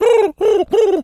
pgs/Assets/Audio/Animal_Impersonations/pigeon_call_sequence_05.wav at master
pigeon_call_sequence_05.wav